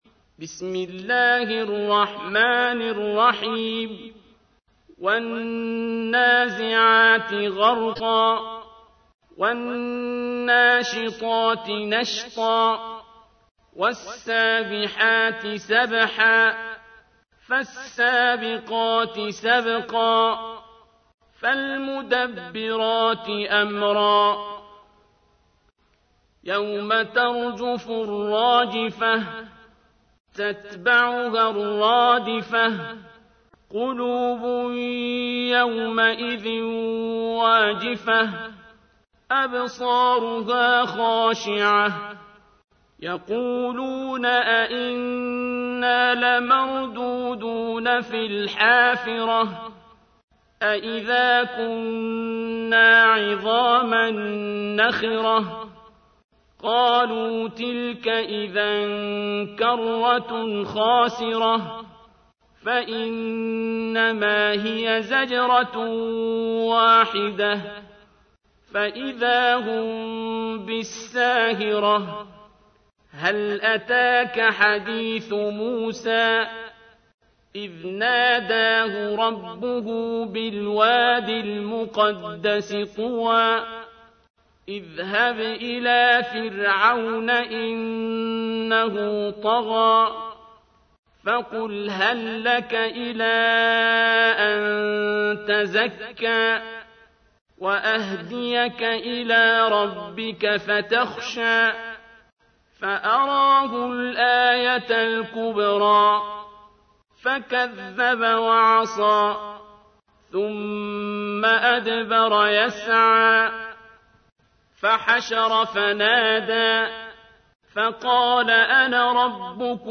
تحميل : 79. سورة النازعات / القارئ عبد الباسط عبد الصمد / القرآن الكريم / موقع يا حسين